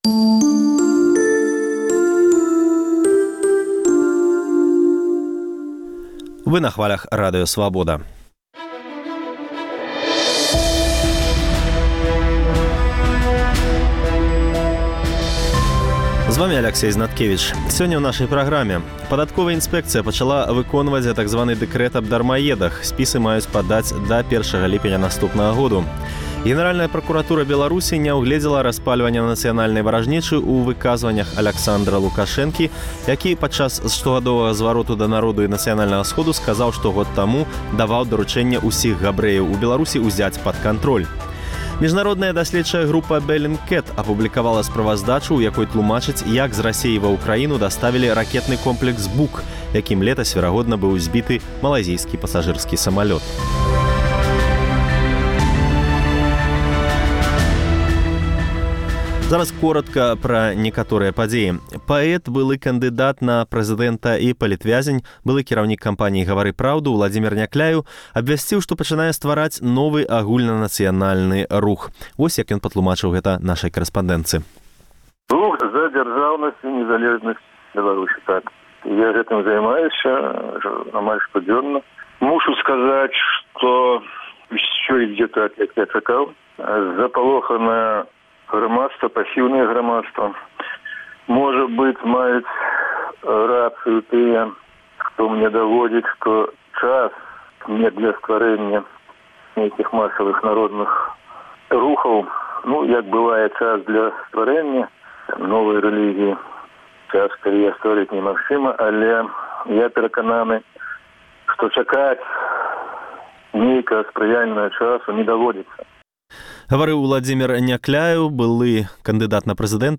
Паведамленьні нашых карэспандэнтаў, госьці ў жывым эфіры, званкі слухачоў, апытаньні ў гарадах і мястэчках Беларусі.